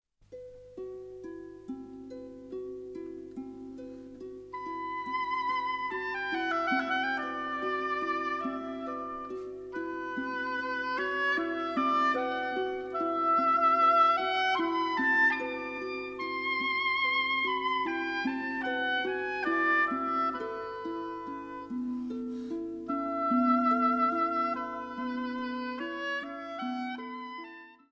Zart